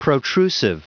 Prononciation du mot protrusive en anglais (fichier audio)
Prononciation du mot : protrusive